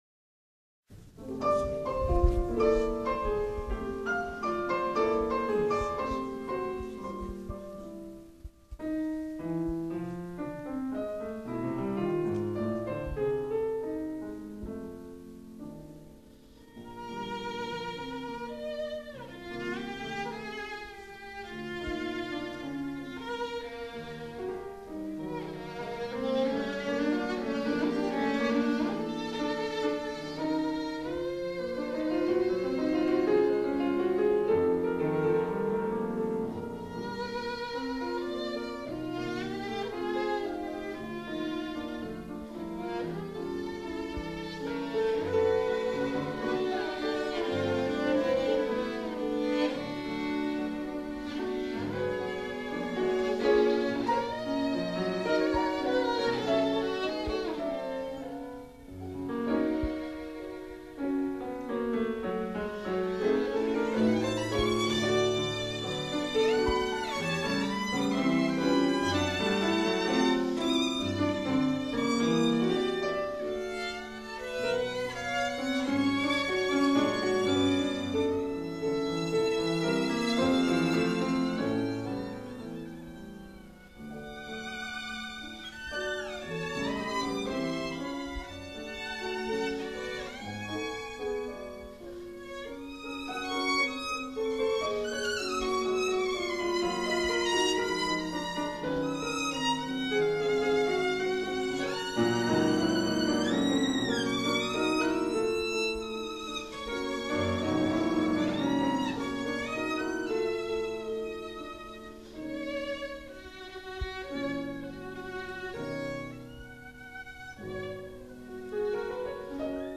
live München 2005